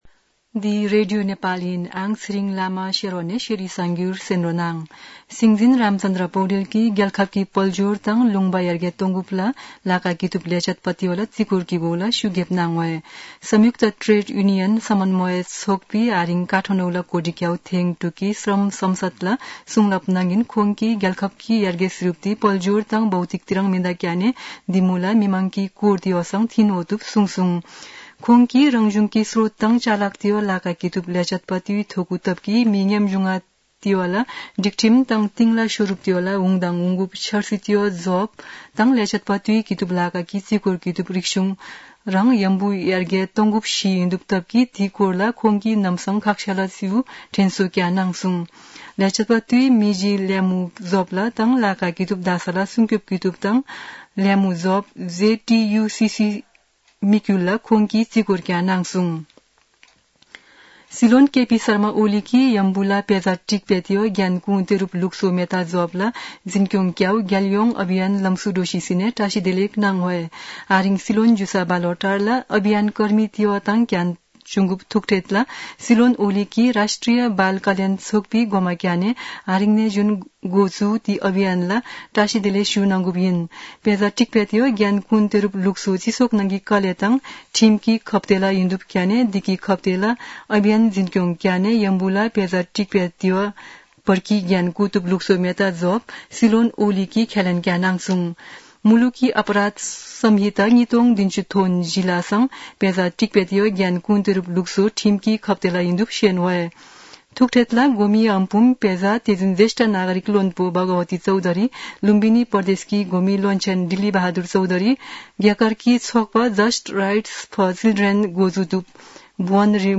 शेर्पा भाषाको समाचार : १७ पुष , २०८१